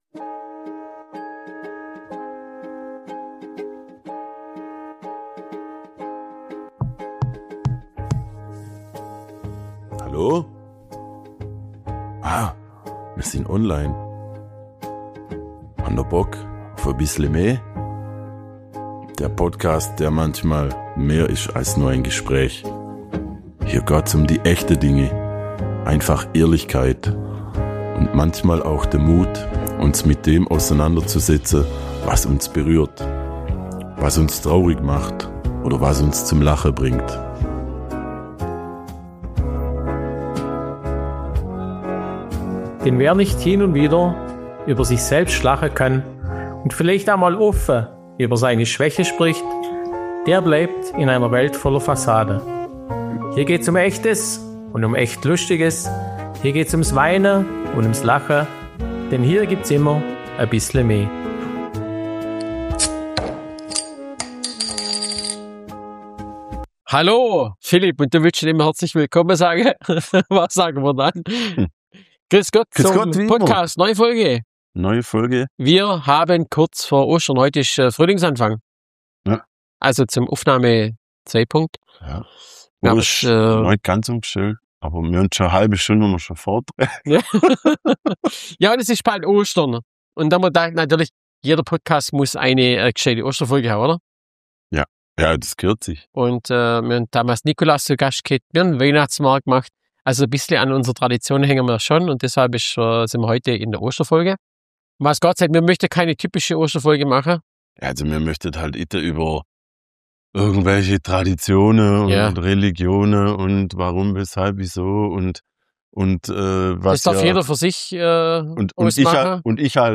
#21 Gsuacht und gfunda. Dr Osterhas zu Gast! ~ Bissle me – Schwoba-Podcast aus´m Schlofsack Podcast